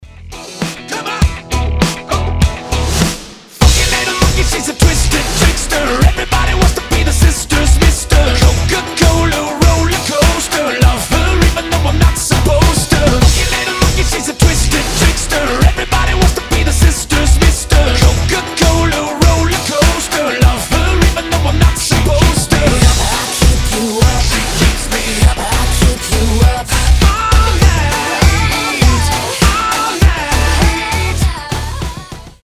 Pop Rock
dance rock